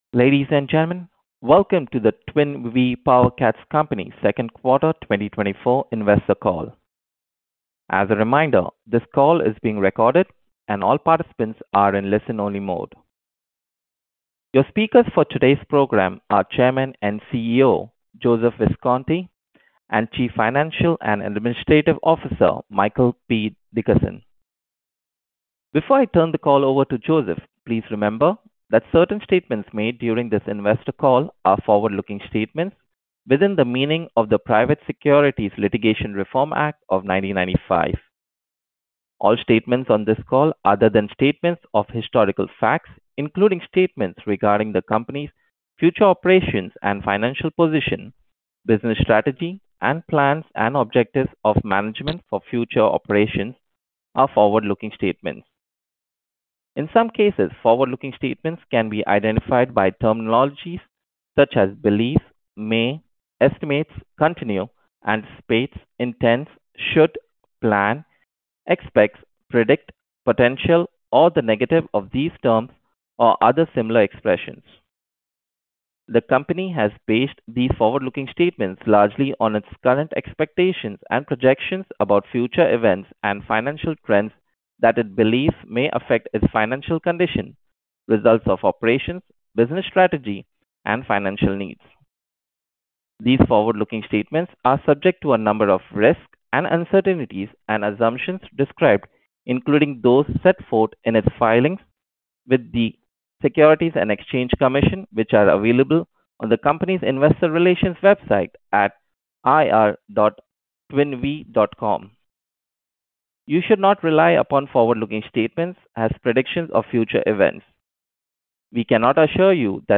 Twin+Vee+Q2+2024+Earnings+Call.mp3